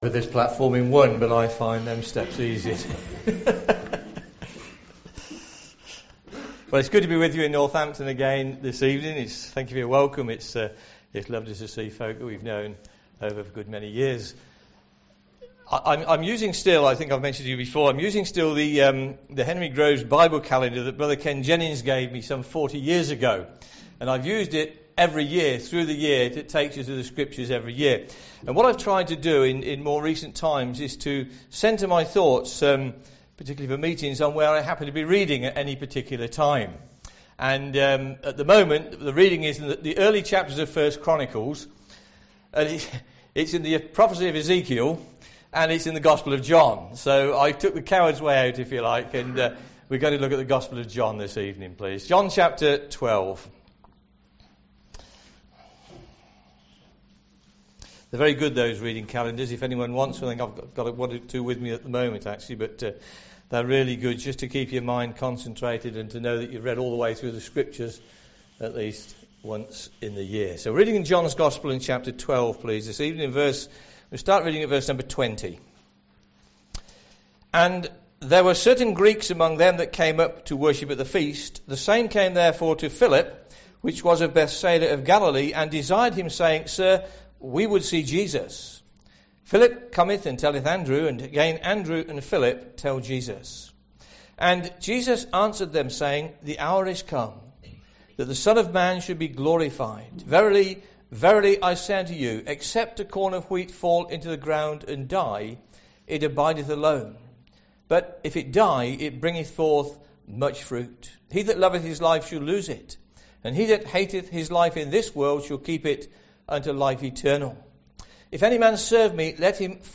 4:15-19 Service Type: Ministry